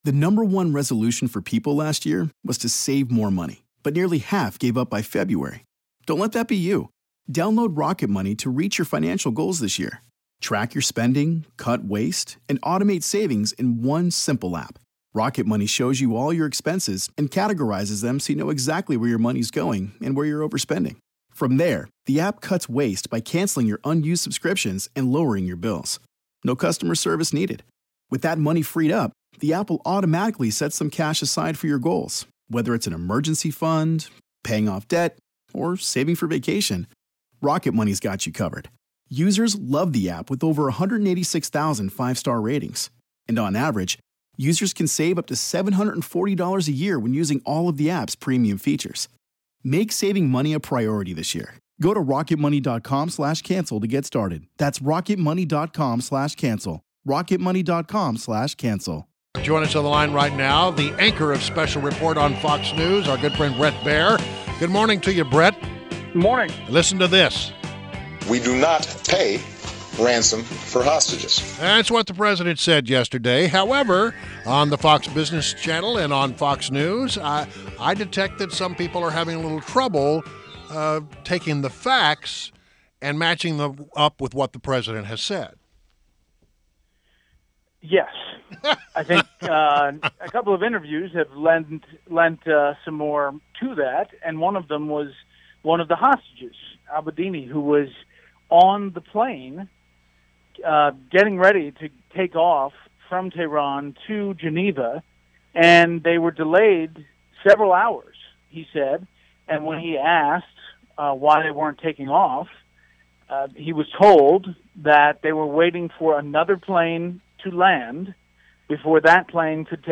WMAL Interview - BRET BAIER - 08.05.16
INTERVIEW -- BRET BAIER - ANCHOR, SPECIAL REPORT, FOX NEWS CHANNEL • TOPICS: Clinton surging in polls, Iran cash deal and mustard • Clinton surges to big lead in McClatchy-Marist poll.